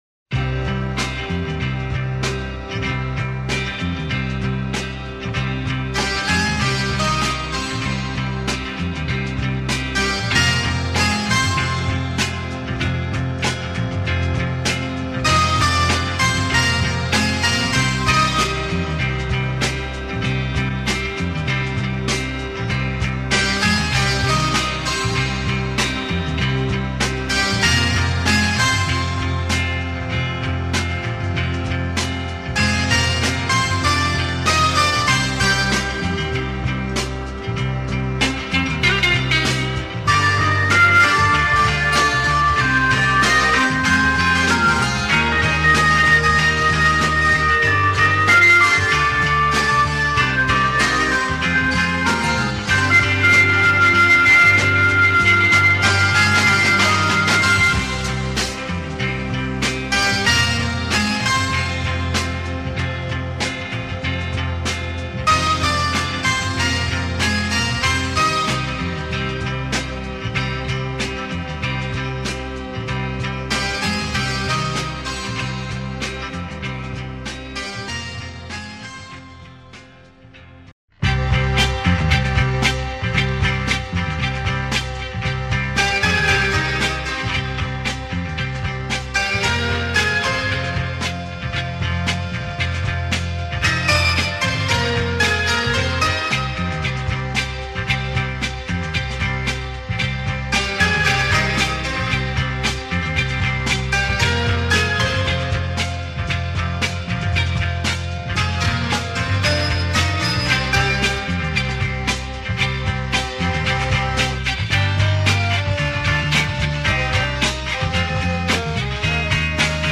Both sides feature music for radio show.
What a groove !
with dope samples), a deep groovy pop number